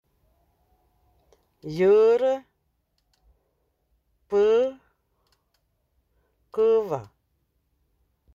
Accueil > Prononciation > üü > üü